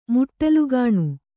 ♪ muṭṭalugāṇu